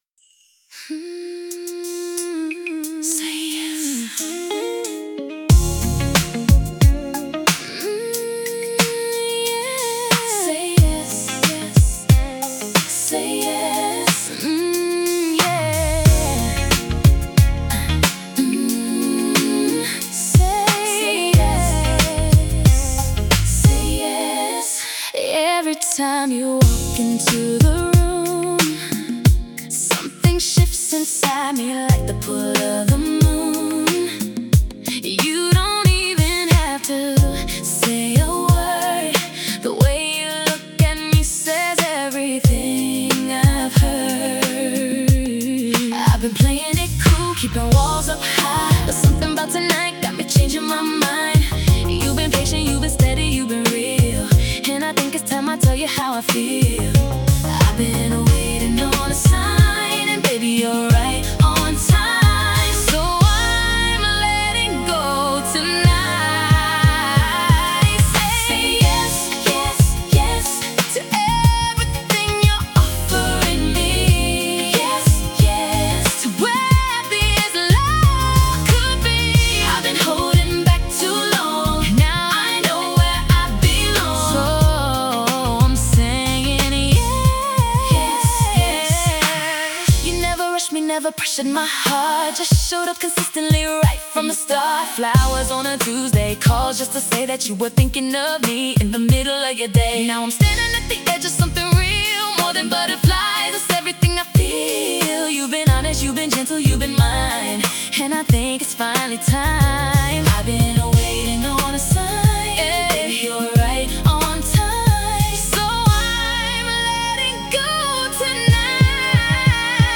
Romantic | Confident 90 BPM